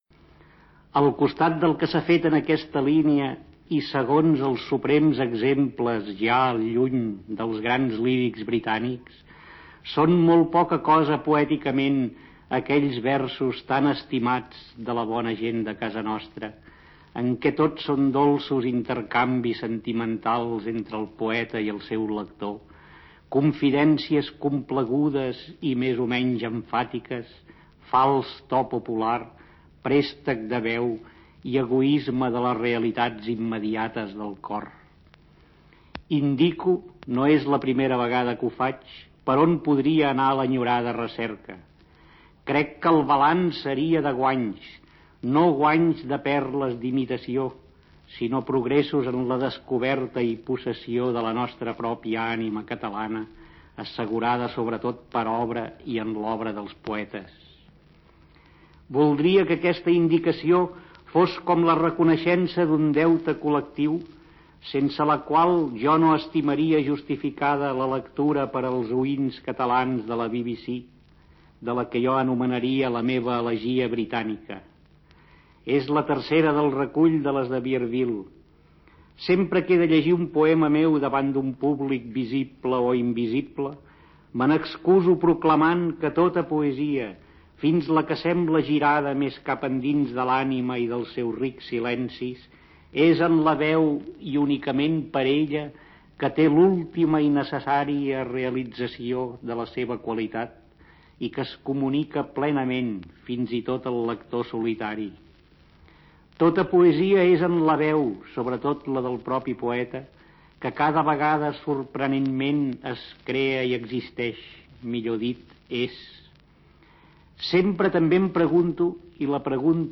Fragment del programa en català de la BBC. Carles Riba parla de la seva obra poètica.